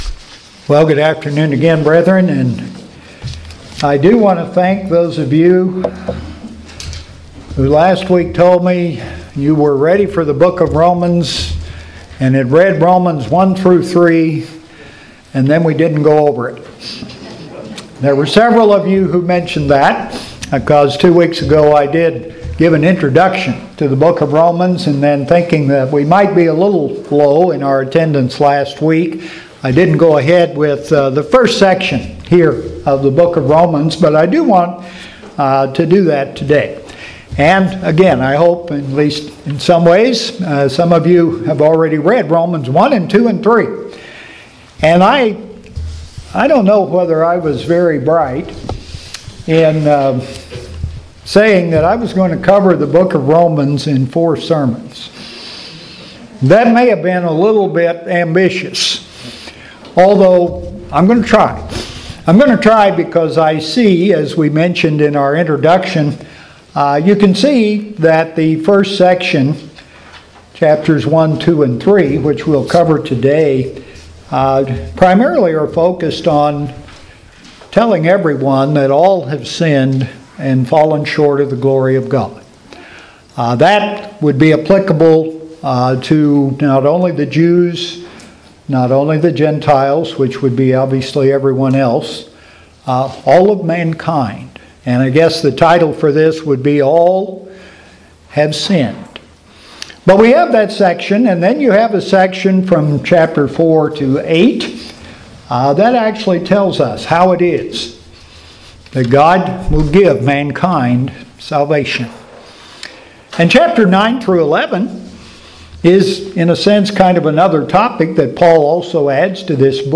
The first in an ongoing series of sermons to cover Paul's epistle to the Romans. This sermon covers the introduction and chapters 1 through 3.